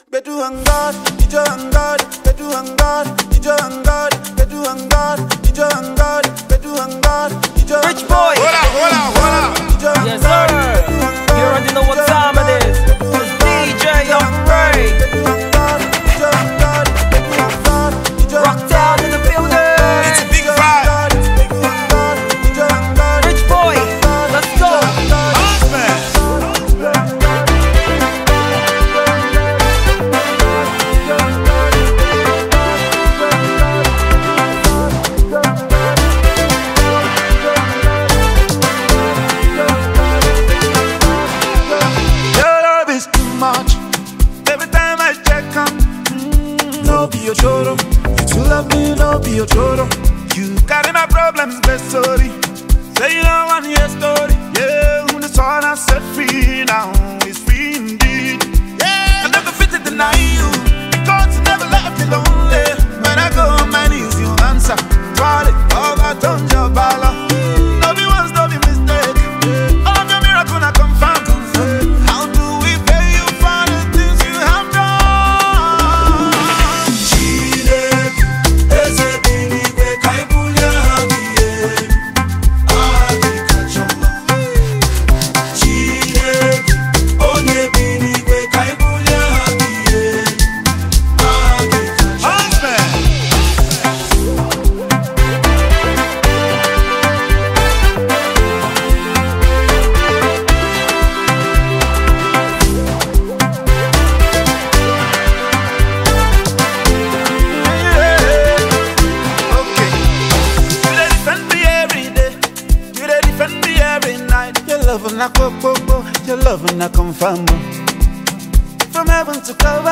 the incredibly talented Nigerian gospel singer